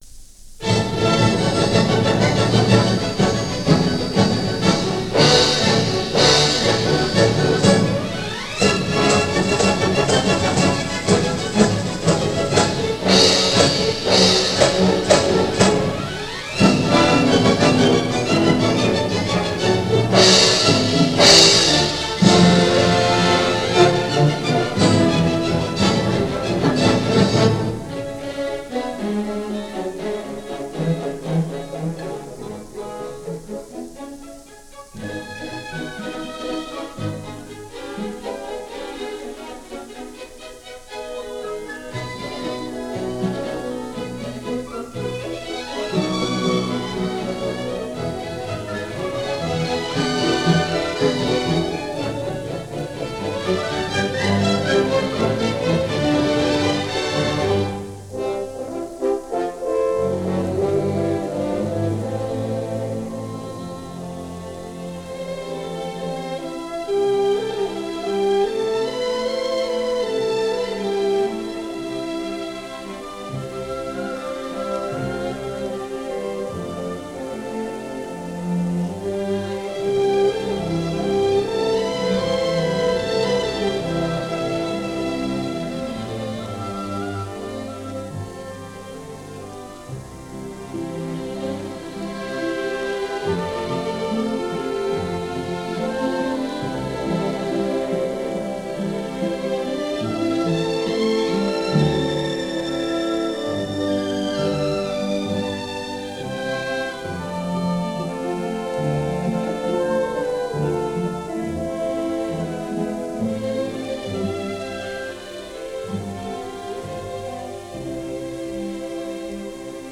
George W. Chadwick – Subtle charm and sense of humor.
Jubilee – Howard Hanson, Eastman-Rochester Symphony
Continuing with more selections from the landmark American Music For Orchestra set of 78 rpm discs (M-608) for RCA-Victor, featuring The Eastman-Rochester Symphony conducted by Howard Hanson from 1939.